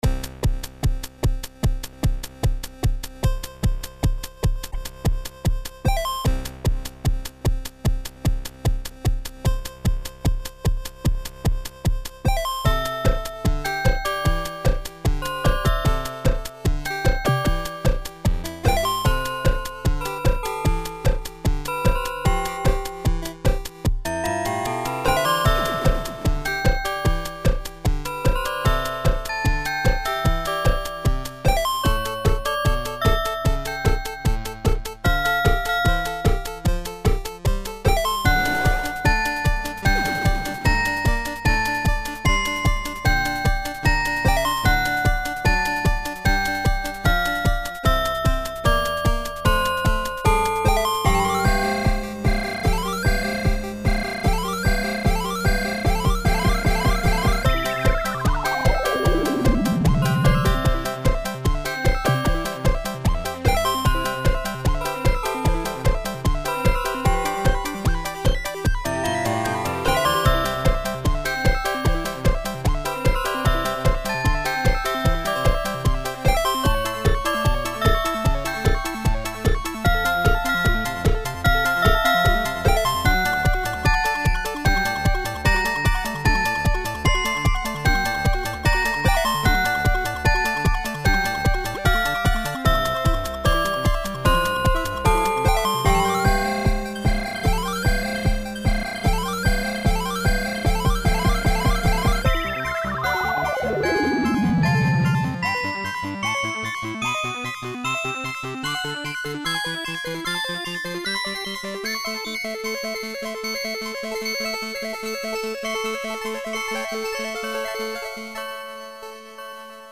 Wah oO, y'a des trucs superbes, je crois que ma préférée est la une avec la reprise de Zelda sur les premières notes.
Most people would say that this song would touch them because of the melancholy of the NES sounds, but I'm from a time period where songs use high quality sounds, so I don't really feel the melancholy. Nevertheless this was a pretty good mix.
Instrumentation is well done though, the beat is okay.